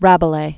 (răbə-lā, răbə-lā, räb-lĕ), François 1494?-1553.